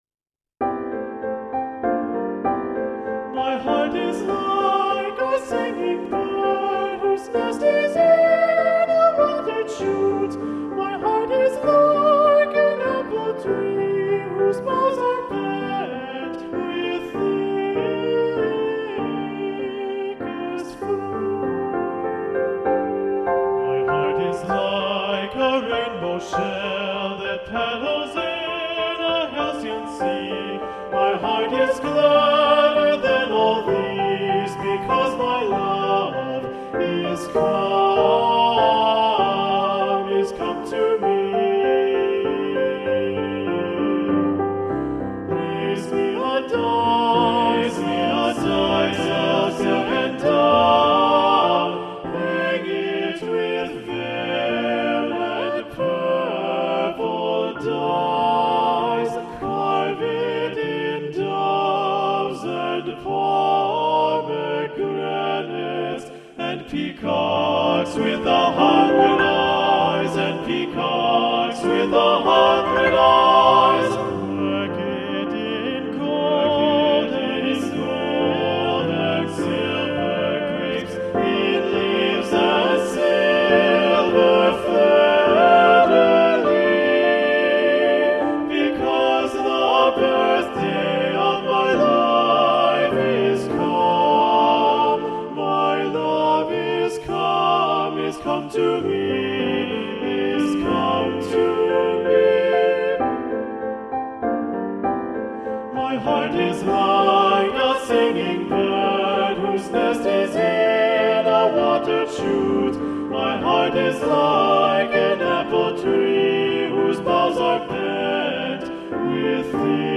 Choral Music
(arrangement of the folk-like song)